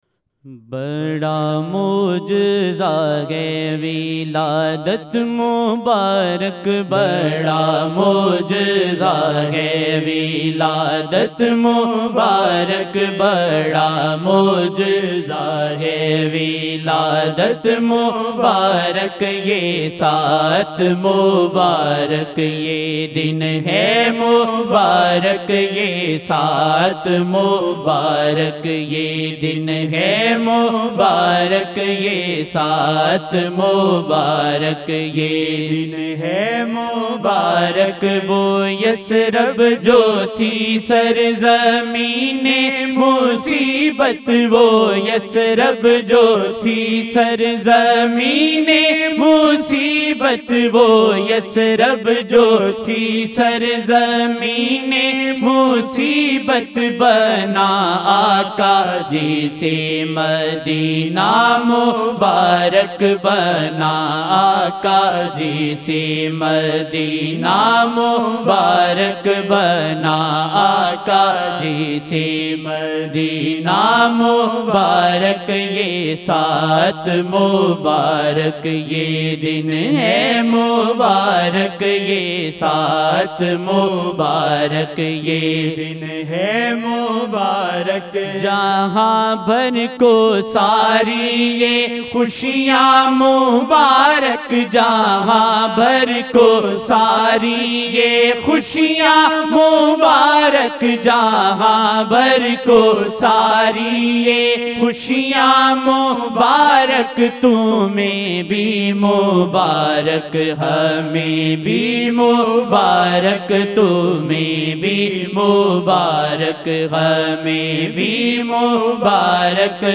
Naat Shareef